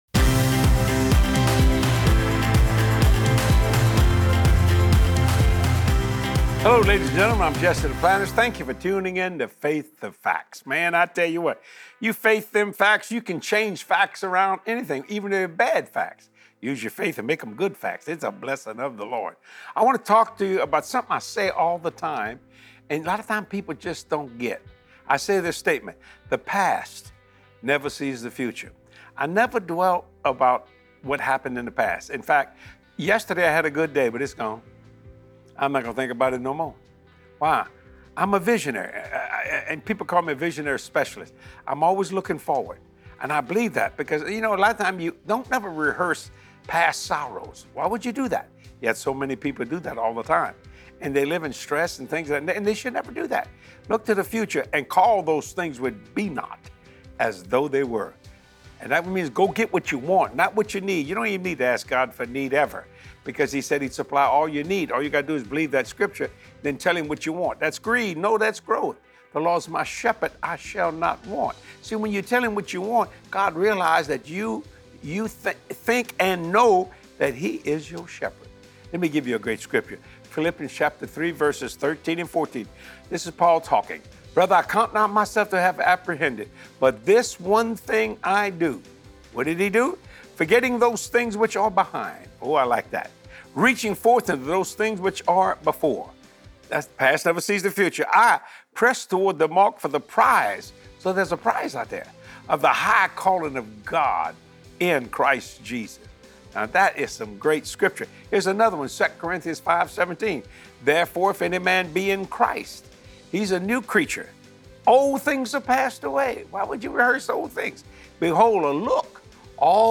God has called you to KEEP MOVING FORWARD! Be encouraged to let go of your past and to walk into your future as you watch this truth-filled teaching with Jesse.